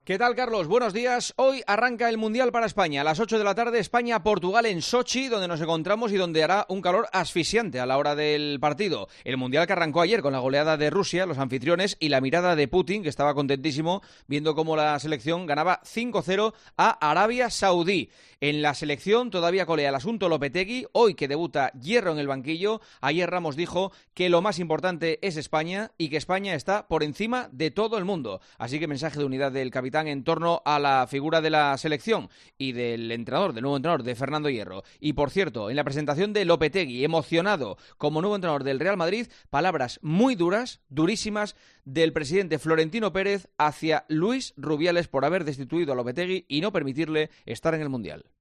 Escucha el comentario del director de 'El Partidazo de COPE', Juanma Castaño, en 'Herrera en COPE'